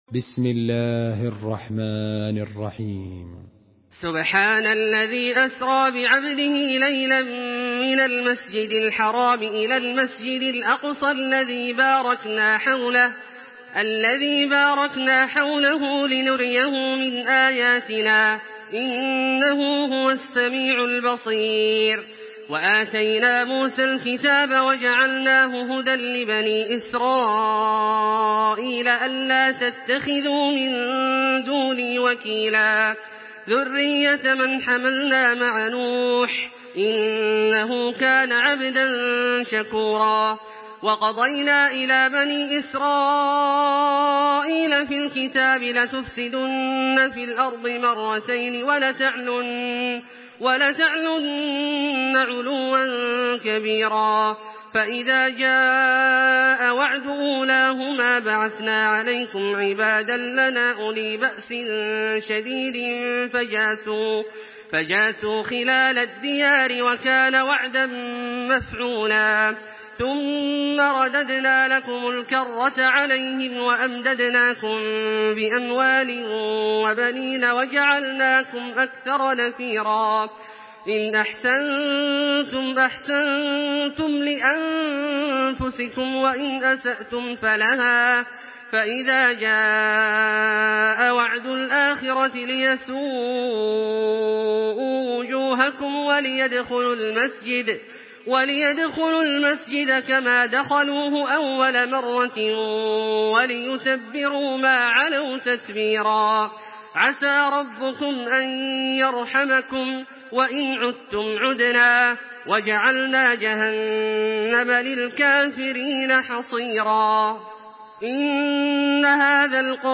تحميل سورة الإسراء بصوت تراويح الحرم المكي 1426
مرتل